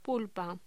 Locución: Pulpa
Sonidos: Voz humana